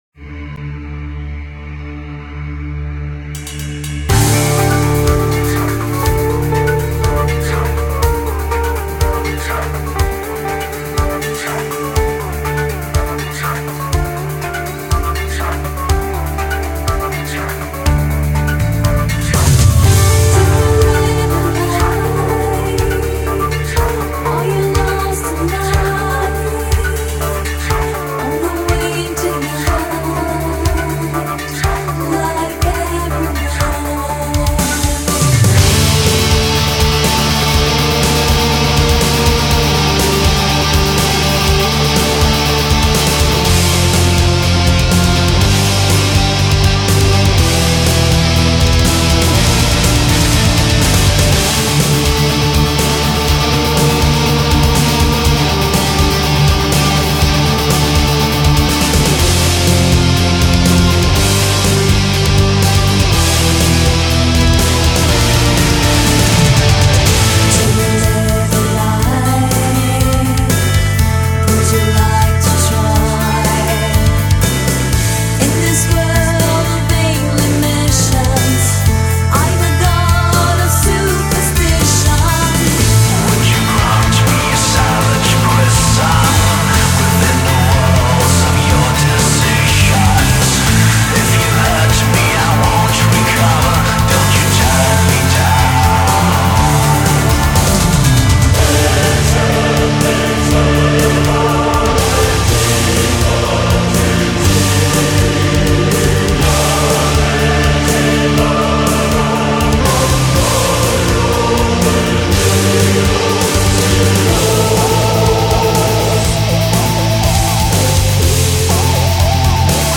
Genre: Gothic Metal